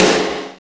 SNR RASPY 01.wav